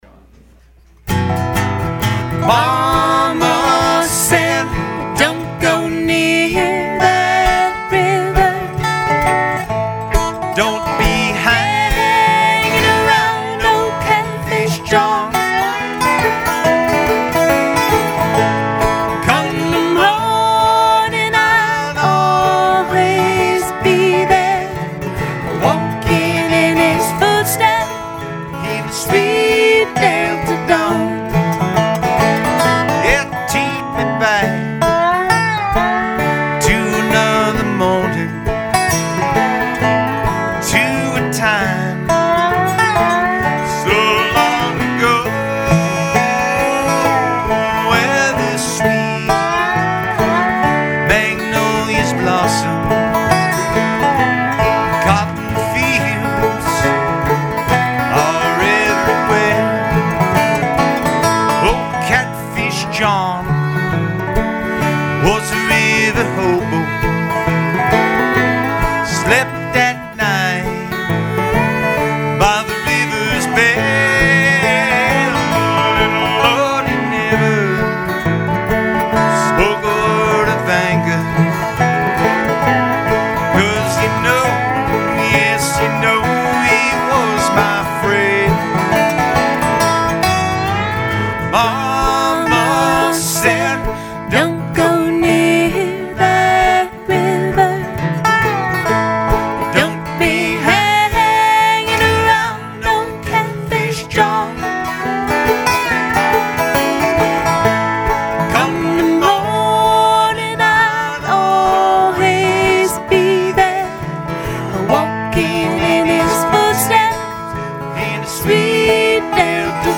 live in-studio performance
Dobro
guitar and vocals
viola, fiddle, banjo and vocals